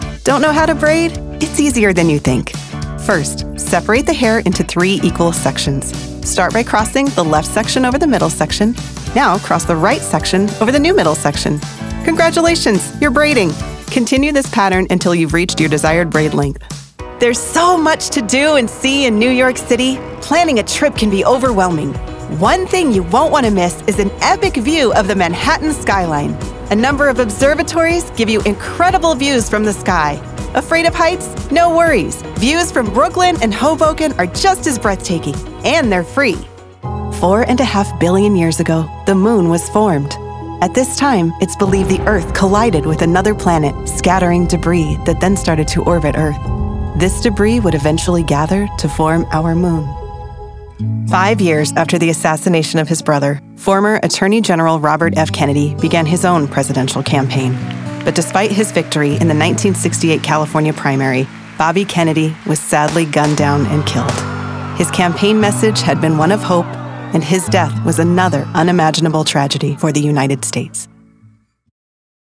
Narration Demo
English - Midwestern U.S. English
English - Western U.S. English
Young Adult
Middle Aged